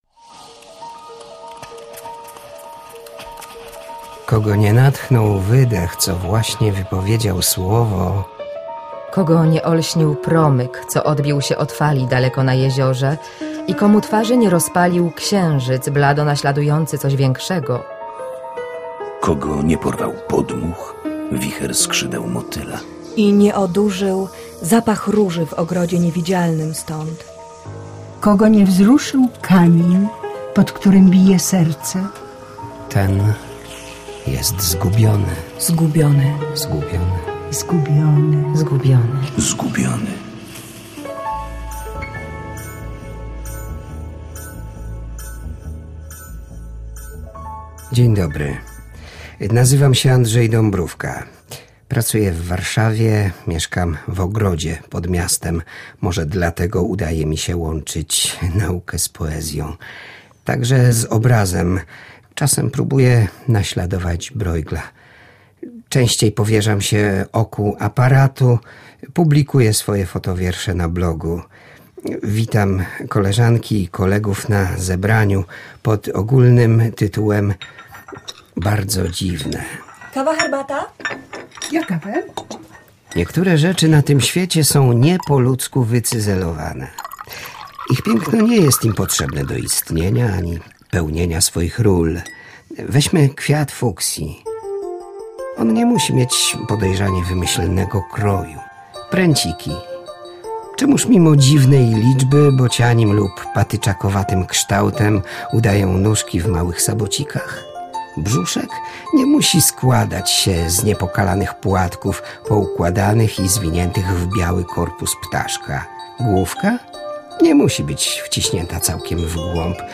Po audycji premierowej – powtórzenie fragmentu słuchowiska poetyckiego pt. „Bardzo dziwne” – scenariusz na podstawie wierszy historyka literatury prof. Andrzeja Dąbrówki. Tzw. „Fotowiersze” to także refleksja nad urodą życia, kolejami losu, przemijaniem.